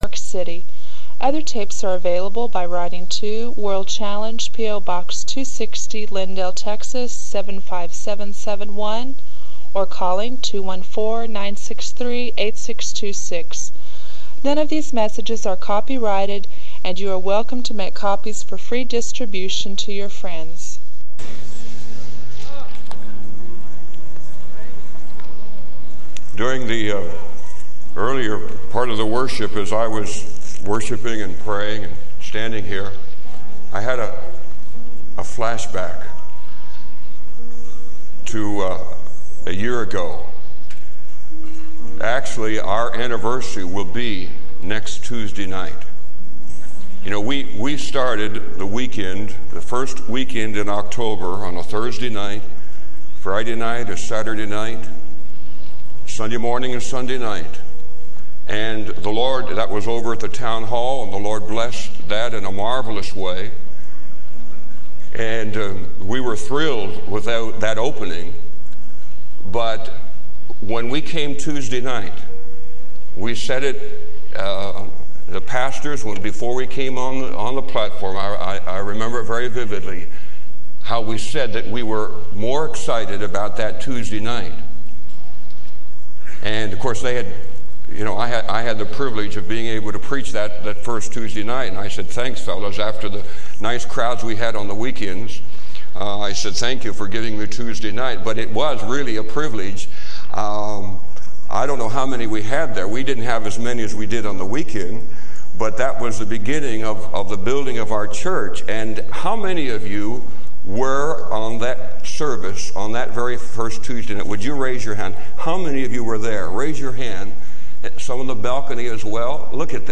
This sermon encourages listeners to embrace patience and spiritual maturity as keys to breakthrough.